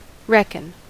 Ääntäminen
IPA: [ˈʃɛtsn̩] IPA: /ˈʃɛ.t͡sn̩/